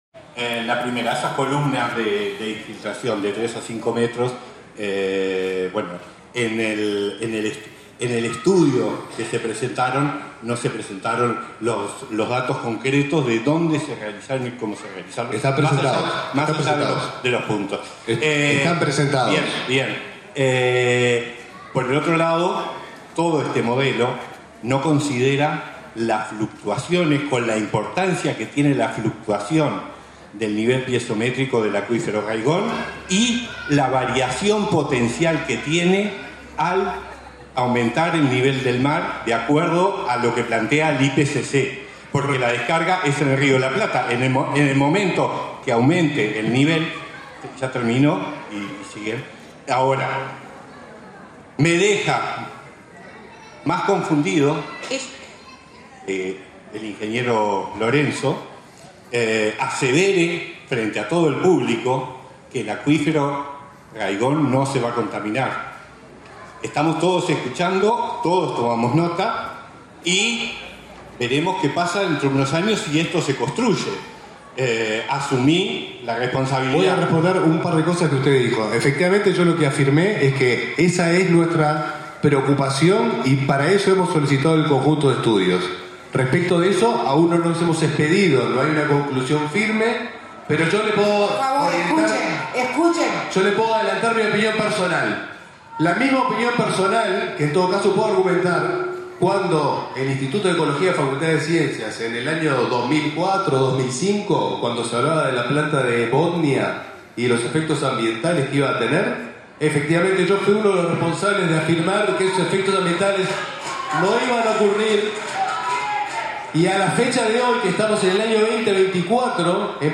Como estaba previsto, desde las últimas horas de la tarde de ayer, se realizó, convocada por el Ministerio de Ambiente,  en el Club San Rafael, de Rafael Perazza,  la «Audiencia Pública» del «Proyecto Arazatí», instancia en la que participaron las máximas autoridades del Ministerio de Ambiente y OSE.